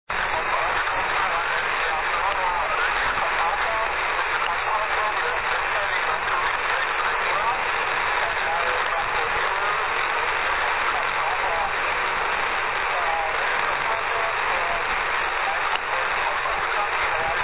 Byl použit Nf kompresor s nastavitelnou frekvenční charakteristikou a FT817.
SIGNAL S KOMPRESI 6 dB (35 KB)